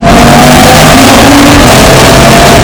RS3 NOISE.mp3